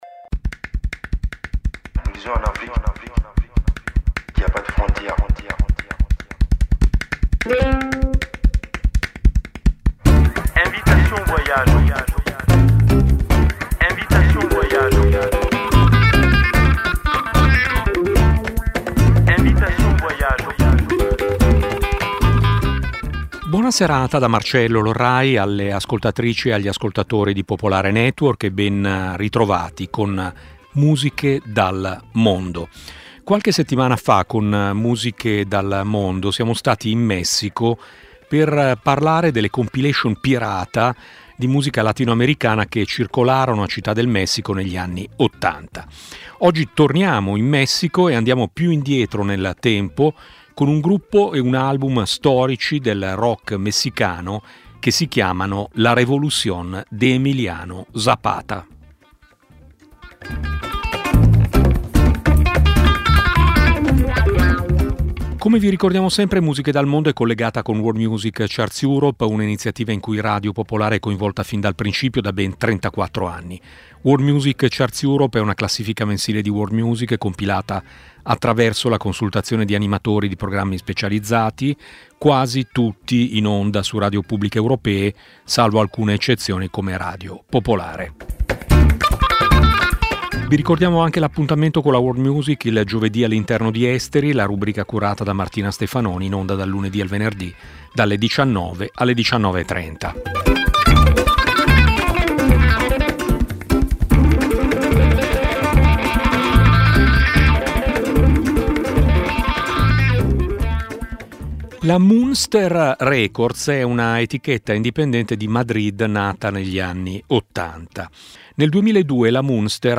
Musiche dal mondo è una trasmissione di Radio Popolare dedicata alla world music, nata ben prima che l'espressione diventasse internazionale.
La trasmissione propone musica che difficilmente le radio mainstream fanno ascoltare e di cui i media correntemente non si occupano. Un'ampia varietà musicale, dalle fanfare macedoni al canto siberiano, promuovendo la biodiversità musicale.